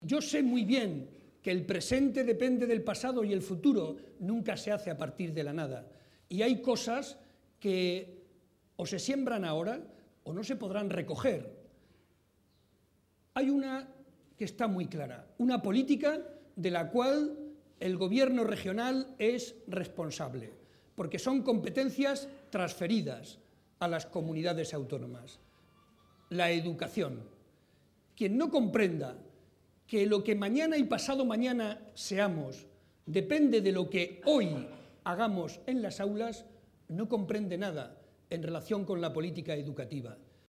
También ha valorado “el entusiasmo, la ilusión y las ideas tan claras” de Magdalena Valerio, a la que ha espetado “¡te veo como alcaldesa!” entre los aplausos de los asistentes, que abarrotaban el Salón de Actos del Conservatorio Provincial de Música.